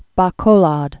(bä-kōlôd)